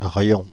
Riom (French pronunciation: [ʁjɔ̃]
Fr-Paris--Riom.ogg.mp3